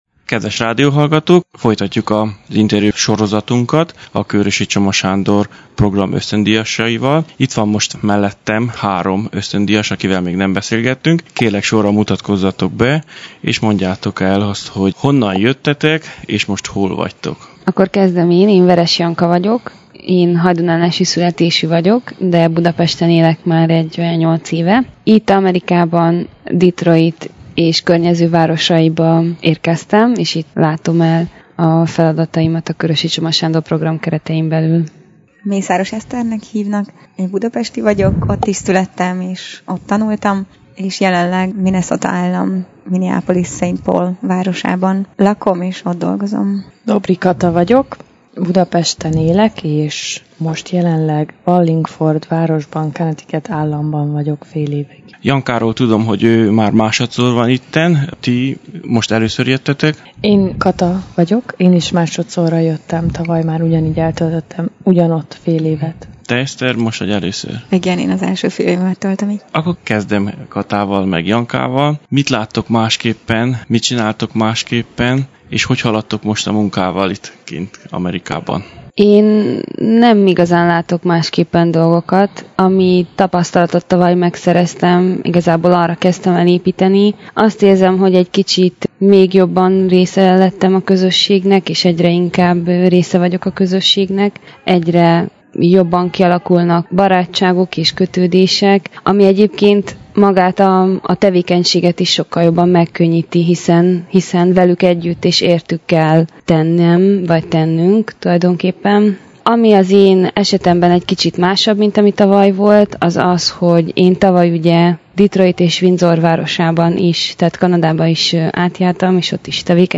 A Csipketáborban találkoztunk több Kőrösi Csoma Sándor Program ösztöndíjassal, akik derekasan résztvettek a gyerekmunkában és különböző adminisztrációs tevékenységekben. Közülük hármat sikerült mikrofonvégre kapni.